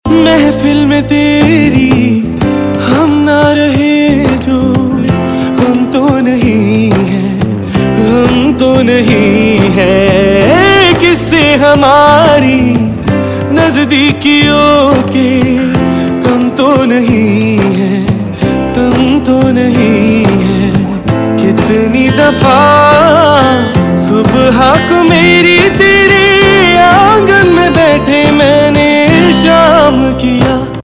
Pop Ringtones